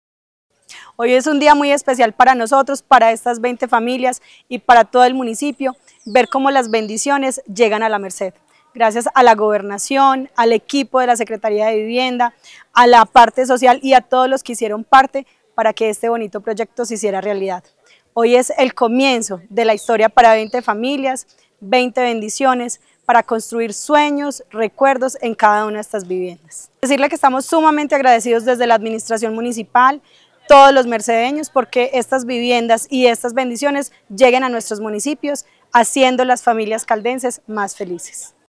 Yeni Fernanda Henao Dávila, alcaldesa de La Merced.